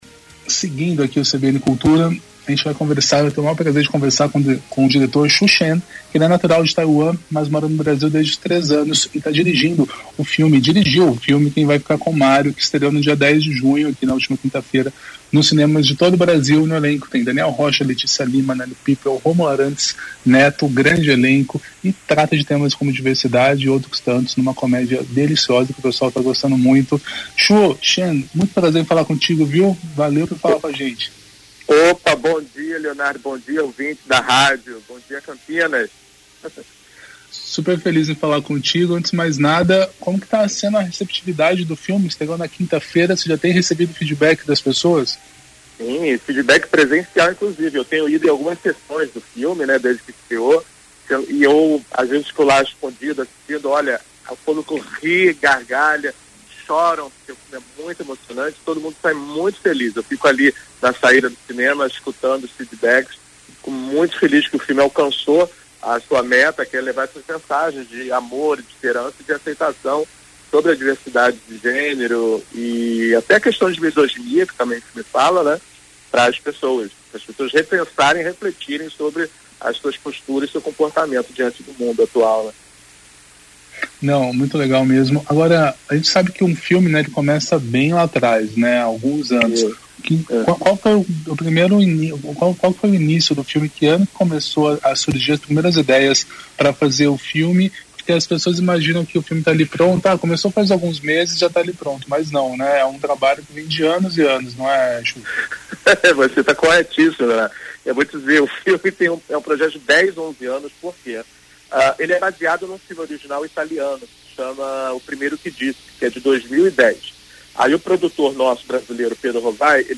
O CBN Cultura entrevistou, ao vivo